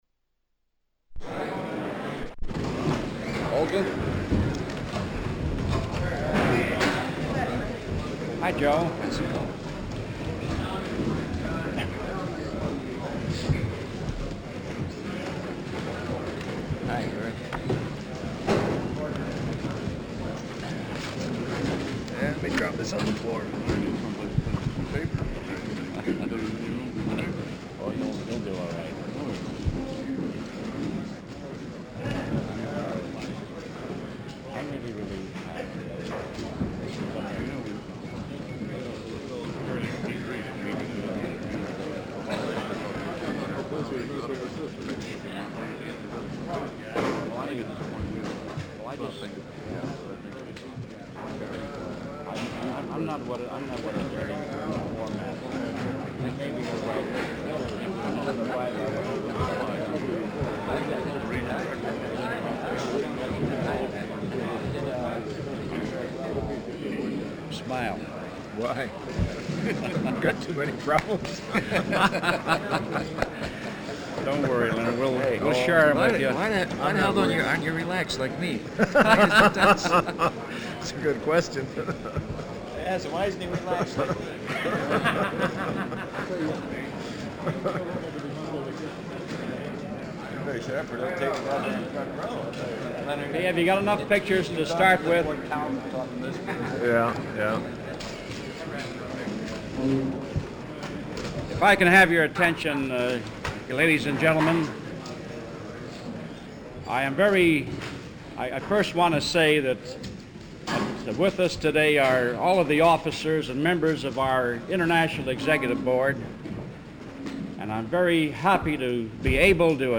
Walter P. Reuther Digital Archive · UAW President Leonard Woodcock - Press Conference - Solidarity House, Detroit, MI · Omeka S Multi-Repository